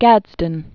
(gădzdən), James 1788-1858.